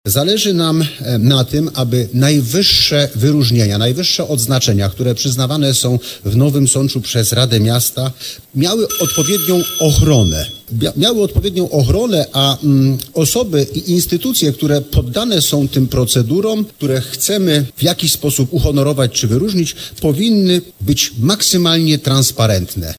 Radni Koalicji Nowosądeckiej nie wzięli udziału w głosowaniu, a jak wyjaśnił szef tego klubu Krzysztof Ziaja, chodzi o to, aby wprowadzić ład legislacyjny i jasne zasady przyznawania tego typu wyróżnień.